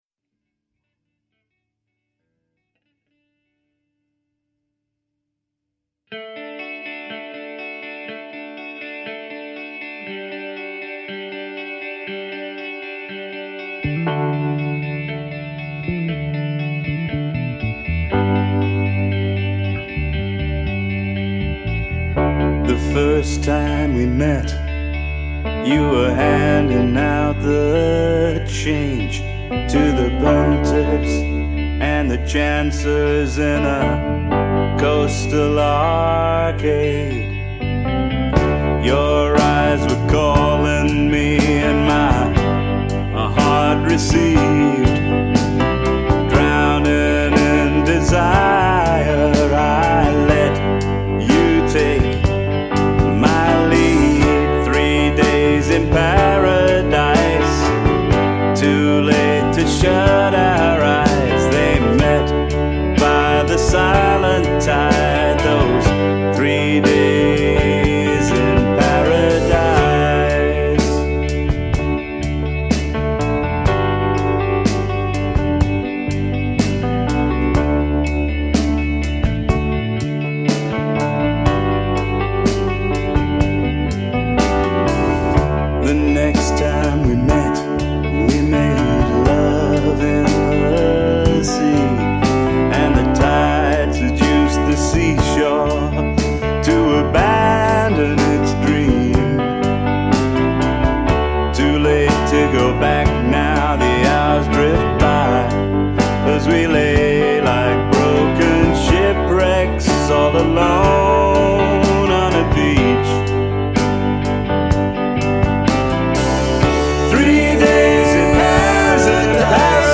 Previously unreleased studio tracks